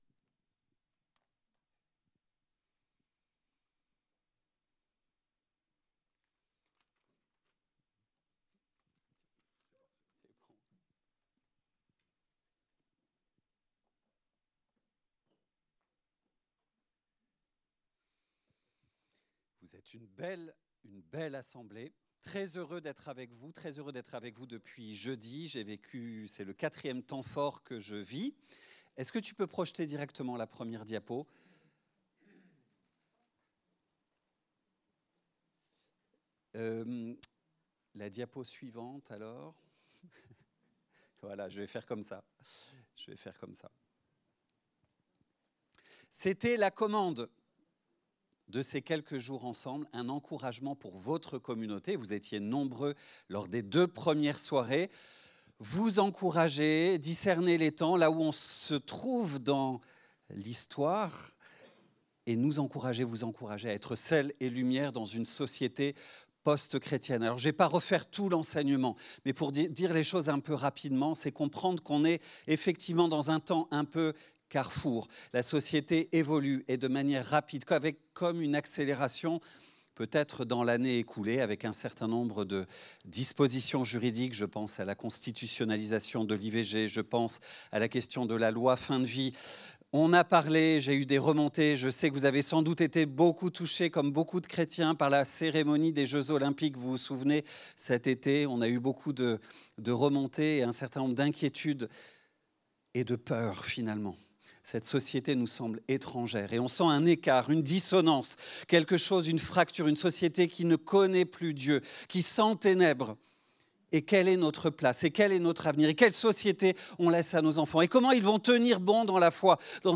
Culte du dimanche 23 février 2025, prédication